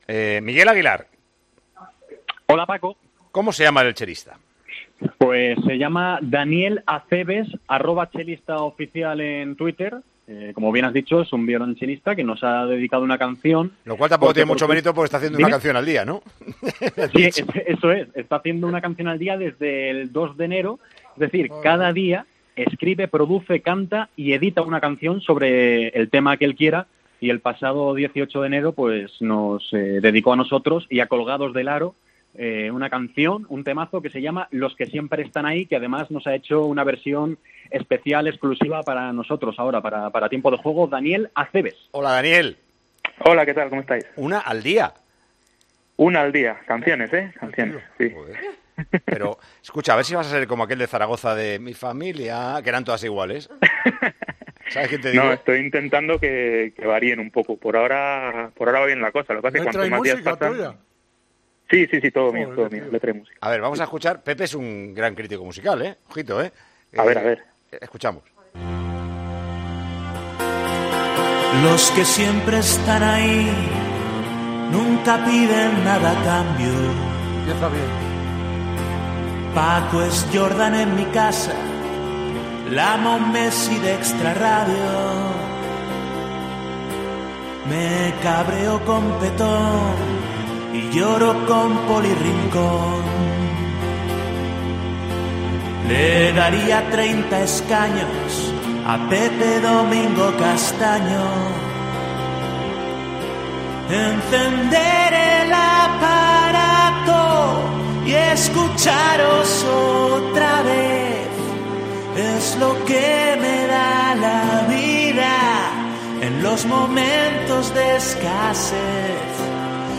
"Los que siempre están ahí": Así es la canción de un violoncelista dedicada a Tiempo de Juego